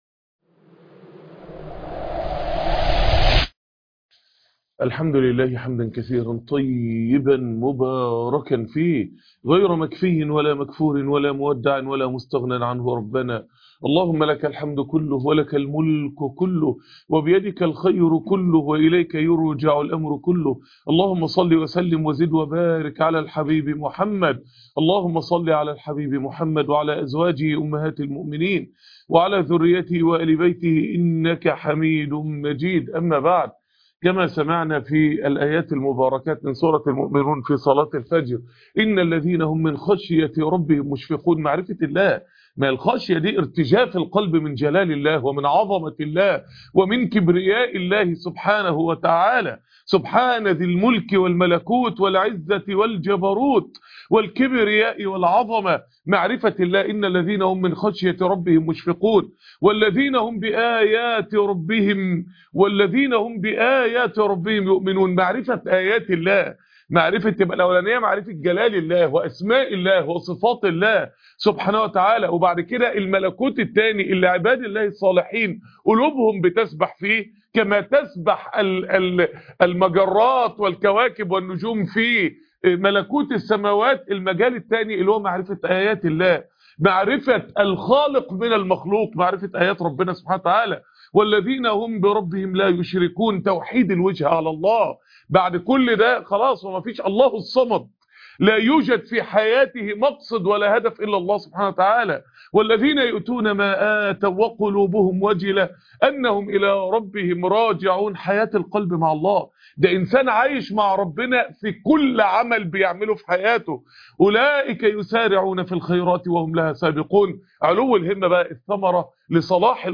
القوانين الربانية لحل جميع مشاكل حياتك .. درس هاام جدااا .